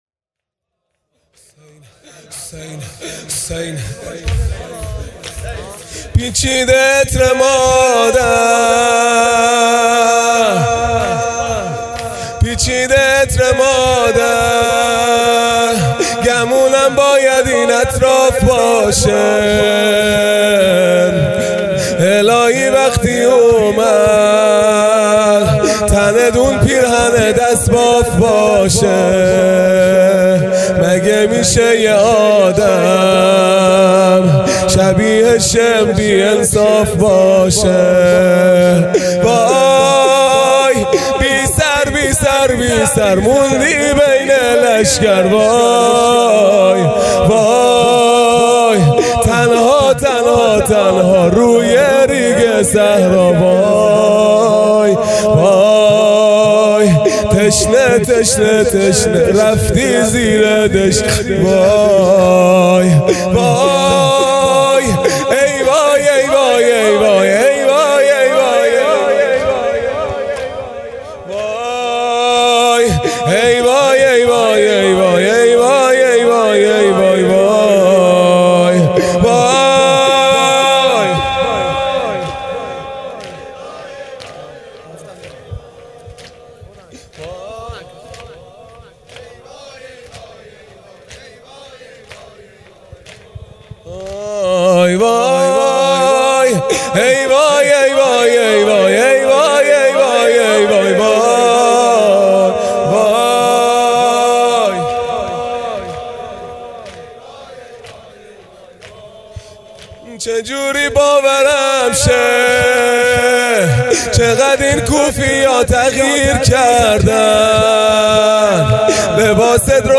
1 0 زمینه| پیچیده عطر مادر
صبح عاشورا